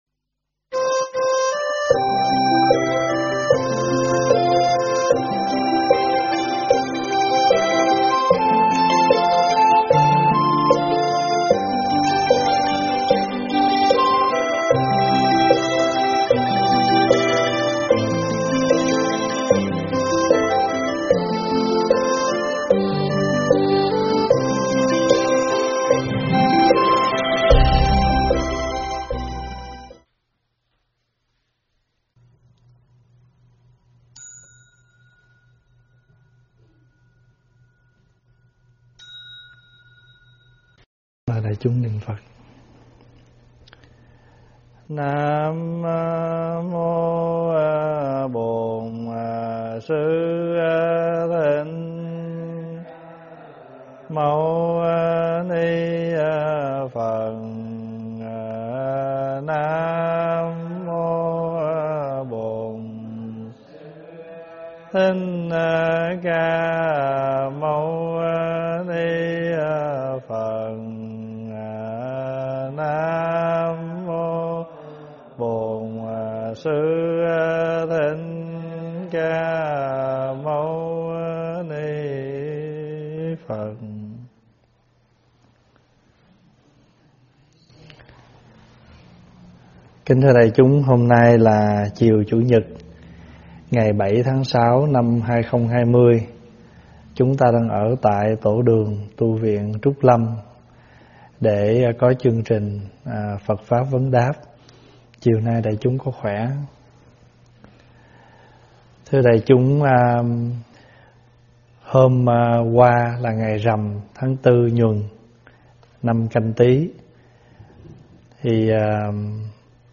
Thuyết pháp Lưới Nghiệp Ngăn Che